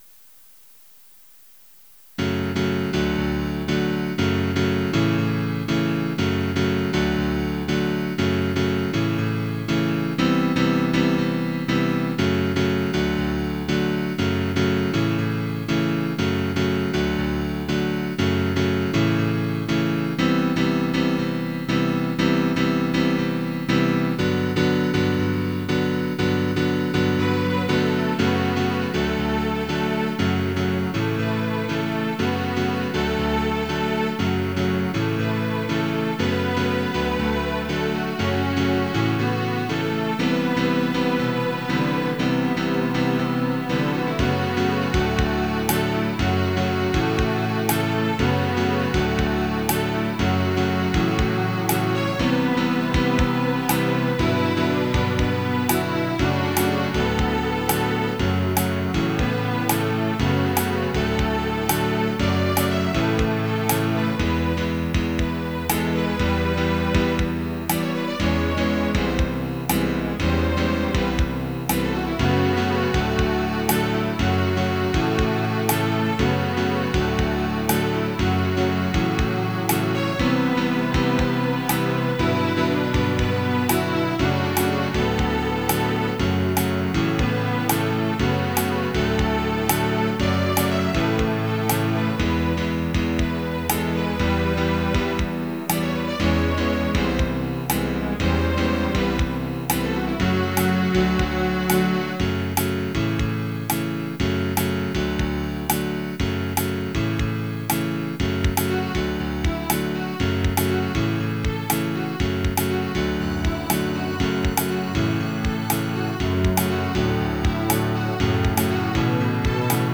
Tags: Quartet, Piano, Strings, Percussion
String Quartet